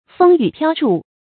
风雨飘飖 fēng yǔ piāo shàn 成语解释 见“风雨飘摇”。